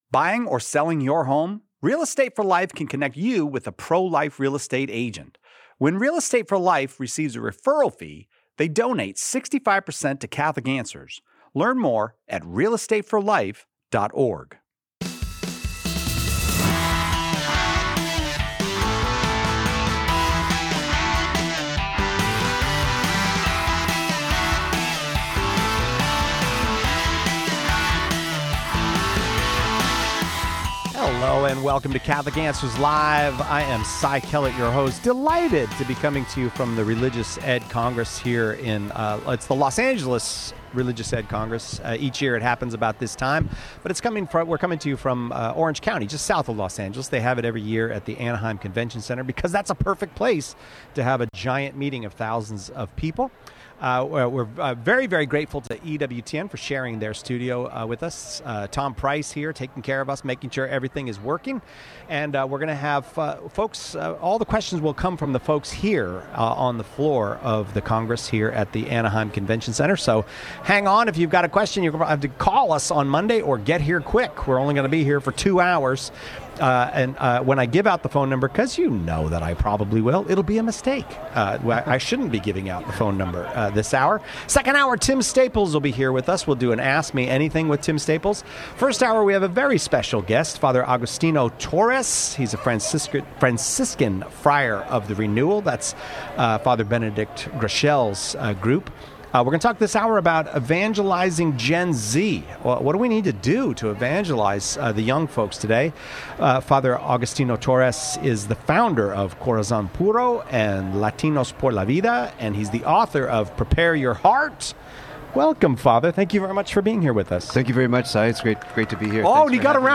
Live from the Los Angeles Religious Education Congress.